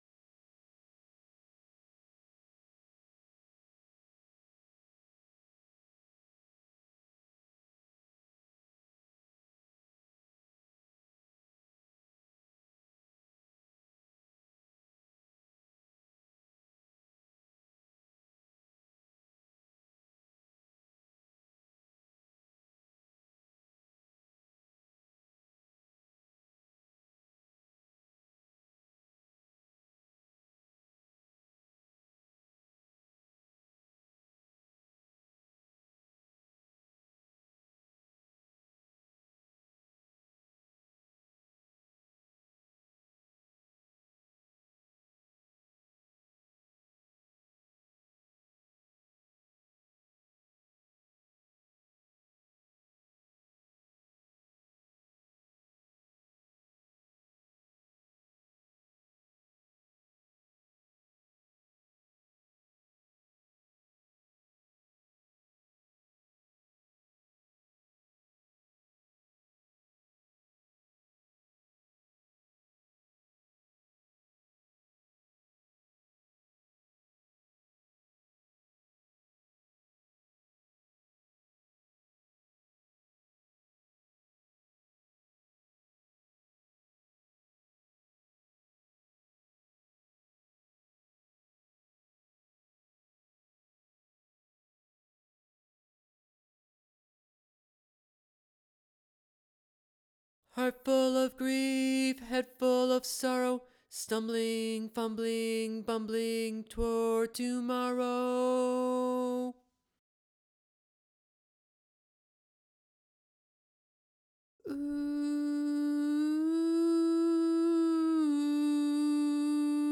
Toward Tomorrow - Vocal Chorus - High.wav